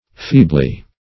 Feebly \Fee"bly\, adv.